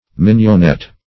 Search Result for " minionette" : The Collaborative International Dictionary of English v.0.48: Minionette \Min`ion*ette"\, a. Small; delicate.